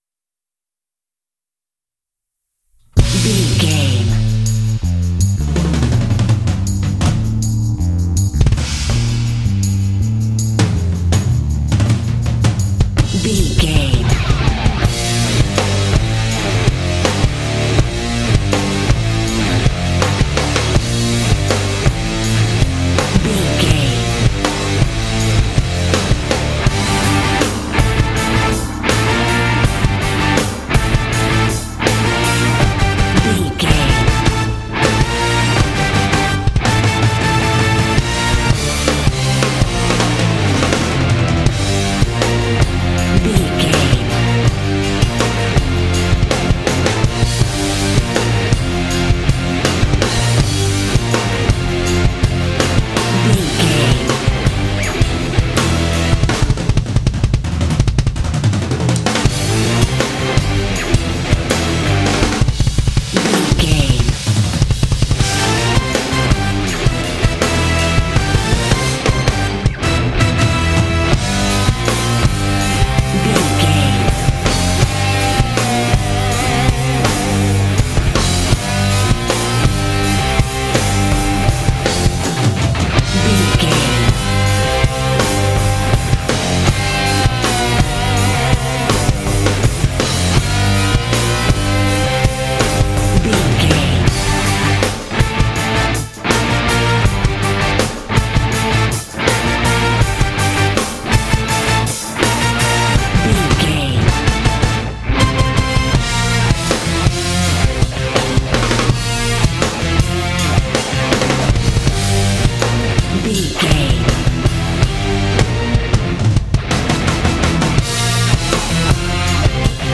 Ionian/Major
D
powerful
energetic
heavy
electric guitar
bass guitar
drums
heavy metal
classic rock